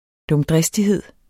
Udtale [ dɔmˈdʁεsdiˌheðˀ ]